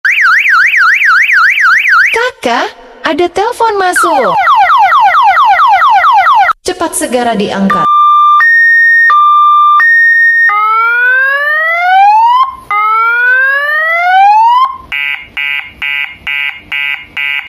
Genre: Nada dering lucu